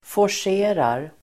Uttal: [får_s'e:rar]